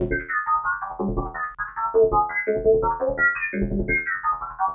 SlightlyAnnoyedComputer.wav